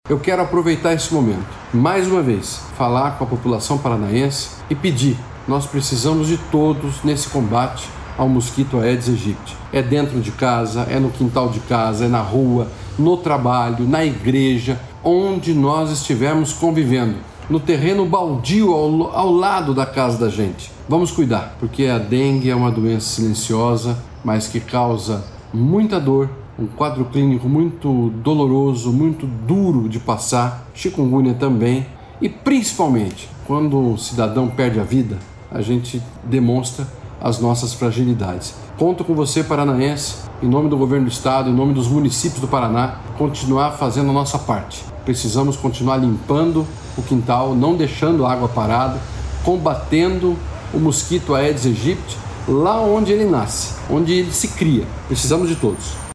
Sonora do secretário da Saúde, Beto Preto, sobre a importância da população eliminar possíveis focos do mosquito da dengue